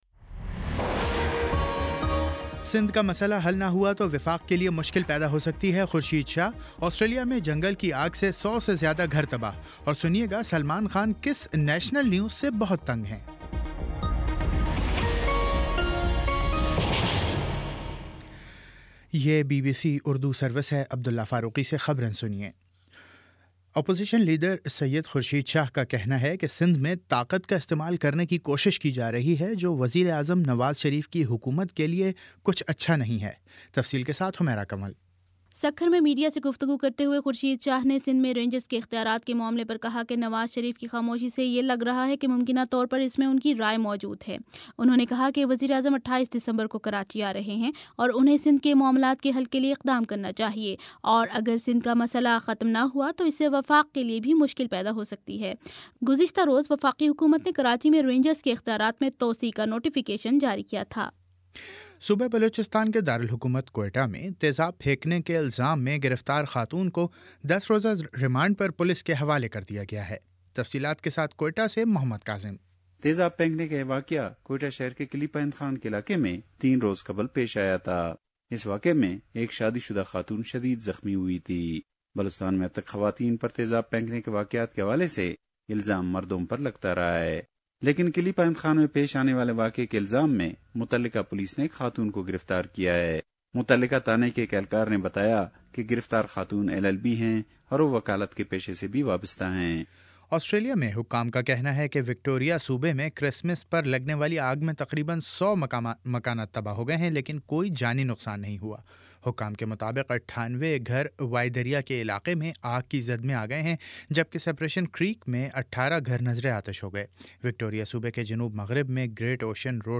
دسمبر 26 : شام پانچ بجے کا نیوز بُلیٹن